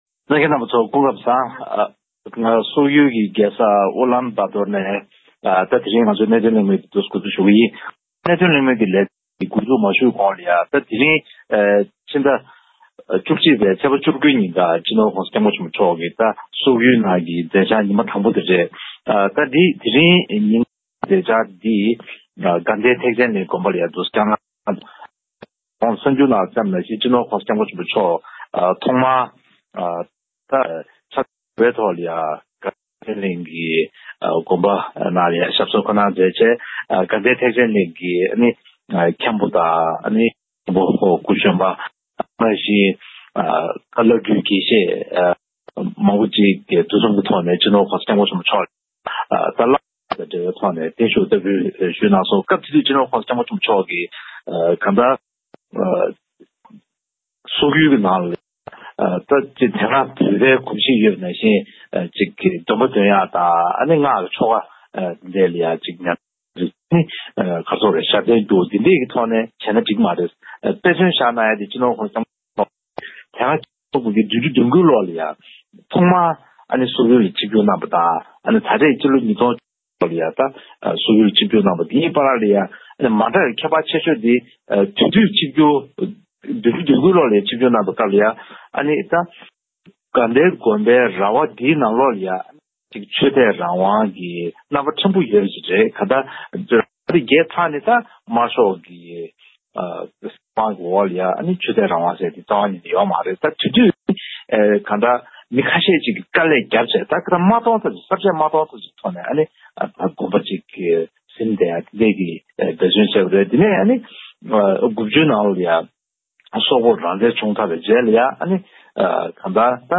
༄༅། །ཐེངས་འདིའི་གནད་དོན་གླེང་མོལ་གྱི་ལེ་ཚན་ནང་། ༸གོང་ས་མཆོག་ད་རེས་སོག་ཡུལ་དུ་ཆིབས་བསྒྱུར་བཀའ་དྲིན་མཛད་པ་དང་བསྟུན། བོད་དང་སོག་པོའི་དབར་གྱི་དམིགས་བསལ་འབྲེལ་བ་དང་། ད་རེས་ཀྱི་༸གོང་ས་མཆོག་གི་ཆིབས་བསྒྱུར་དེ་གནད་འགག་ཆེ་ལོས་གང་འདྲ་ཆགས་ཀྱི་ཡོད་པའི་སྐོར་སོགས་ལ་གླེང་མོལ་ཞུས་པར་གསན་རོགས་གནང་།